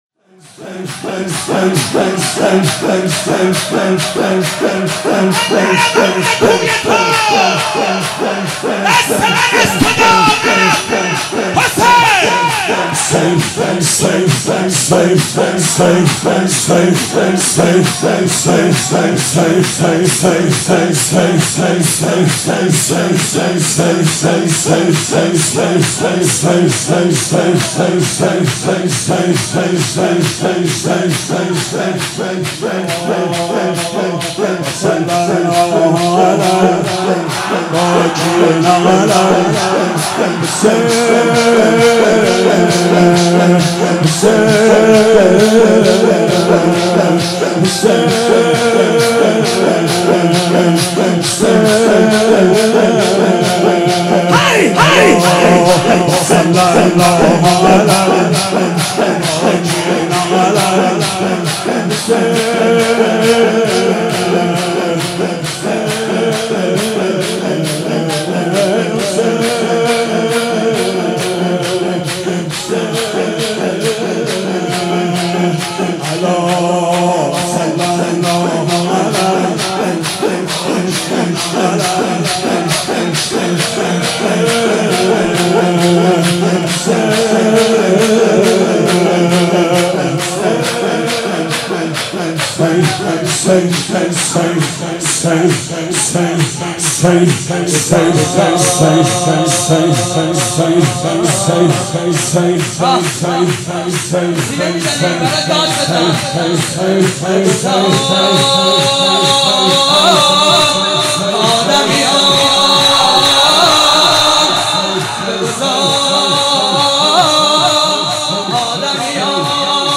مناسبت : وفات حضرت زینب سلام‌الله‌علیها
مداح : محمود کریمی قالب : شور